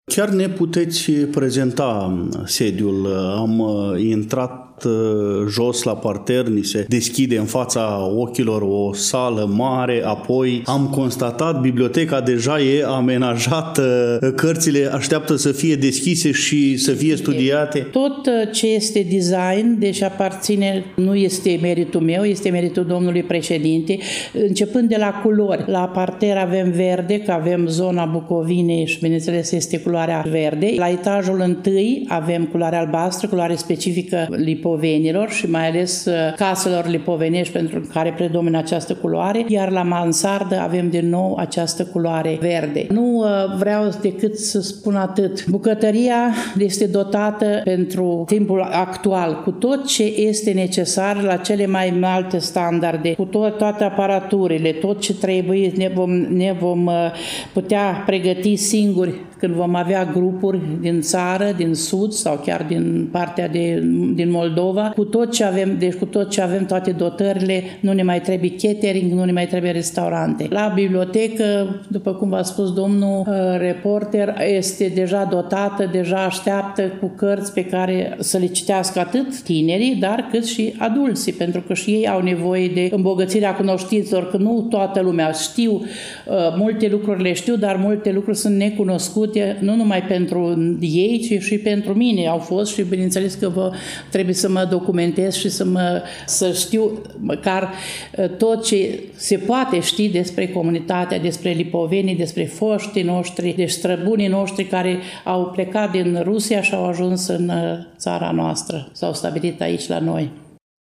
Nu ne rămânde decât să începem dialogul cu invitatul ediției de astăzi a emisiunii noastre și să aflăm povestea Centrului de Cultură și Educație din sânul Comunității Rușilor Lipoveni Rădăuți, cu accent pe descrierea acestuia.